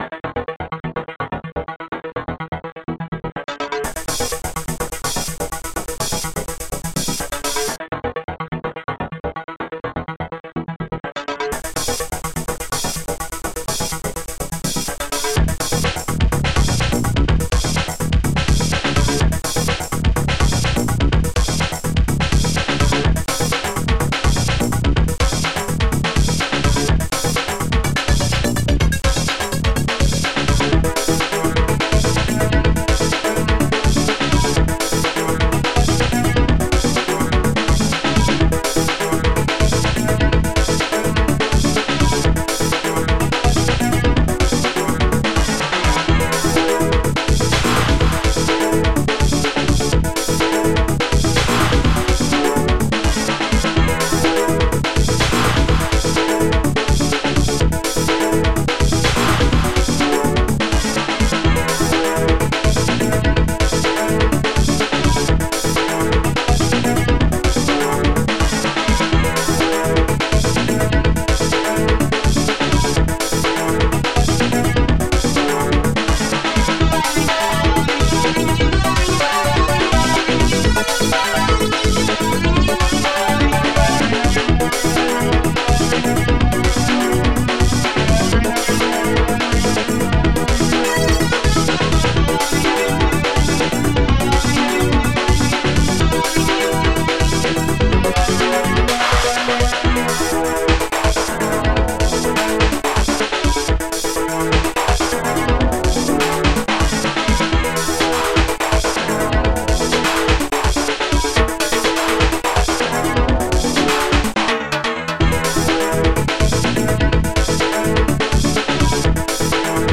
Protracker Module  |  1990-11-25  |  130KB  |  2 channels  |  44,100 sample rate  |  3 minutes, 27 seconds
st-01:electronicguitar
ST-11:polysynth4
st-01:powerclap